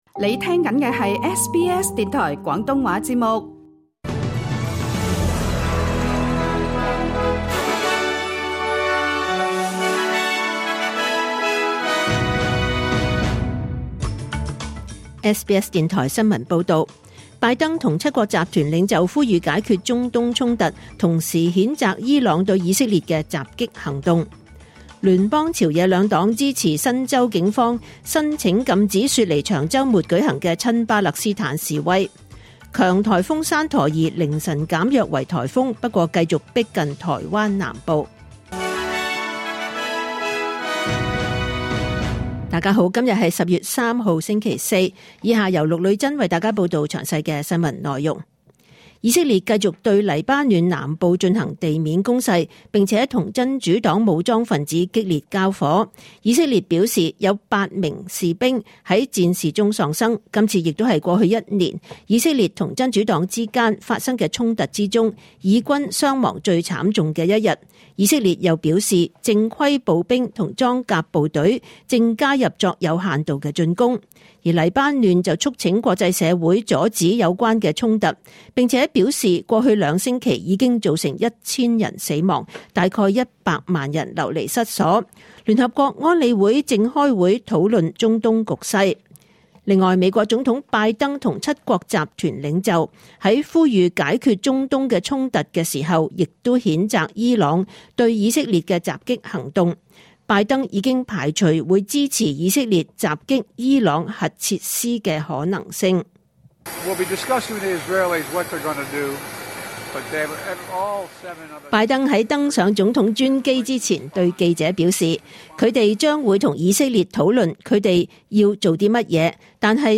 2024 年 10 月 3 日 SBS 廣東話節目詳盡早晨新聞報道。